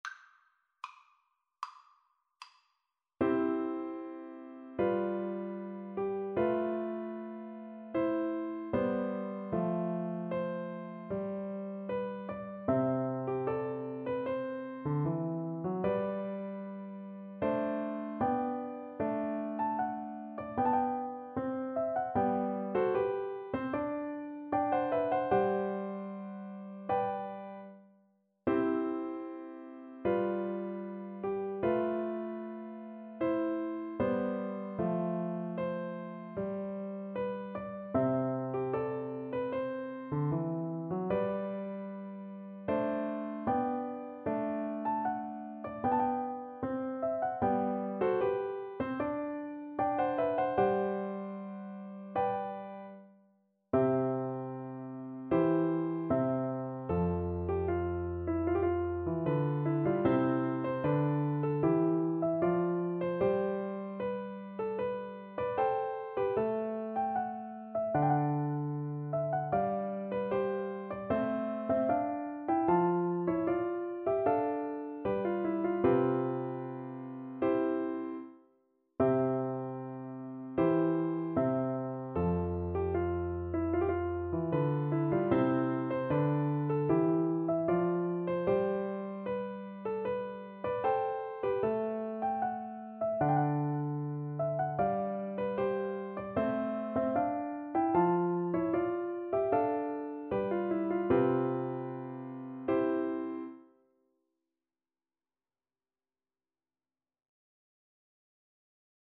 Andante =76
Classical (View more Classical Cello Music)